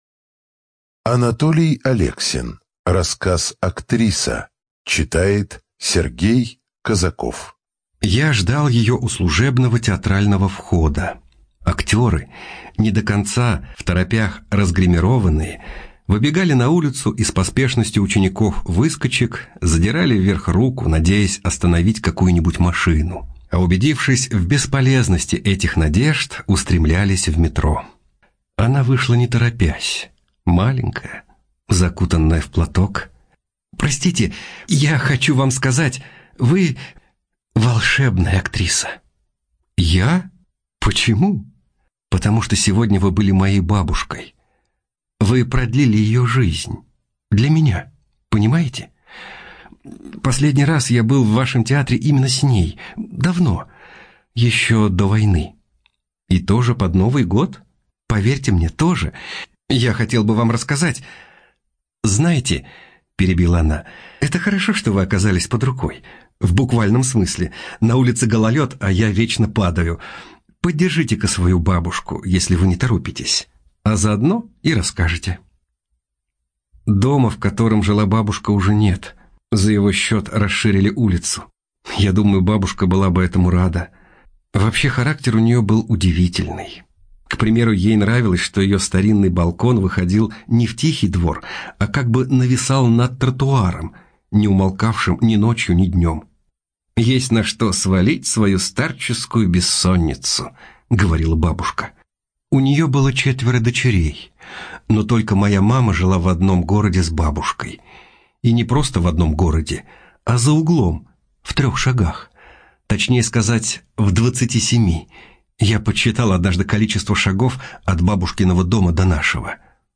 Студия звукозаписиТРК "Звезда"